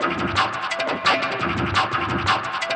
SURGE LO-FI.wav